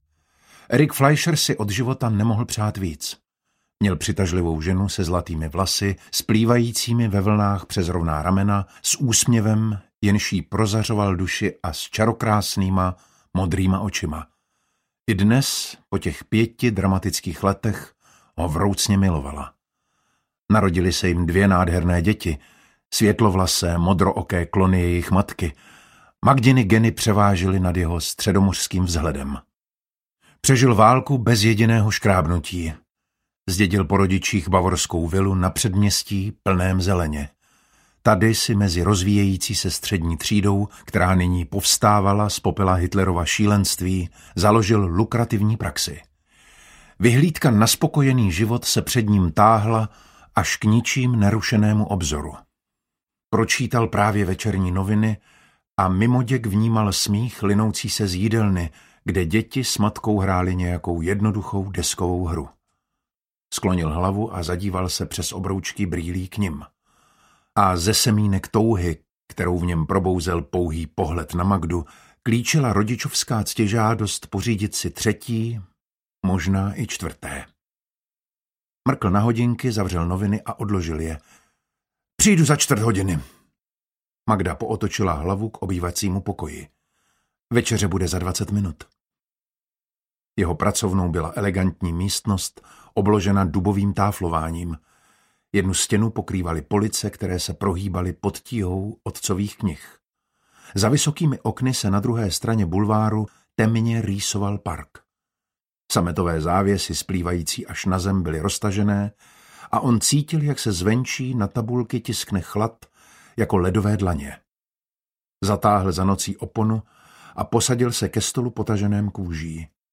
Entomologův odkaz audiokniha
Ukázka z knihy